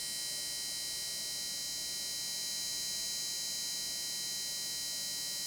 DLPLCR4500EVM: Coil whine
The board emits relatively loud (rough measurement using my phone is 75+ dB) and flat spectrum noise.
2. The noise starts immediately on power up
3. It would seem the noise is concentrated on the backside of the board near where the inductors are.